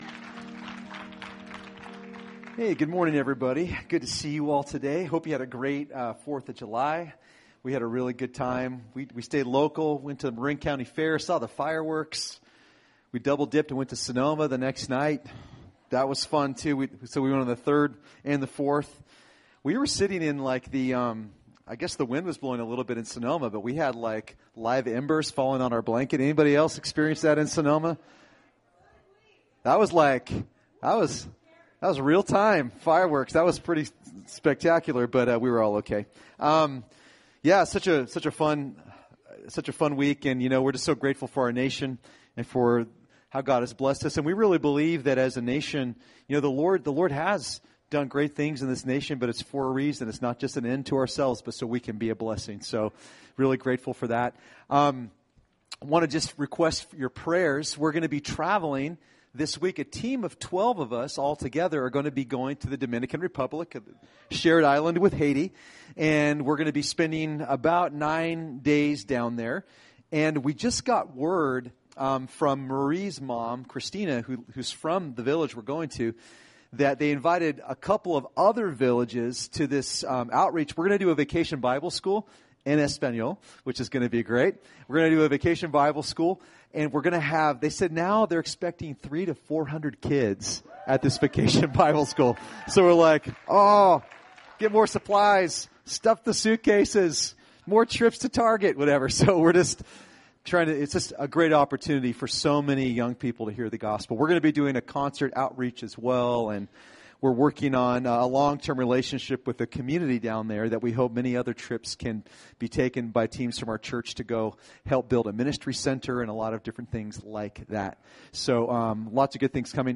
Recorded at New Life Christian Center, Sunday, July 8, 2018 at 11 AM.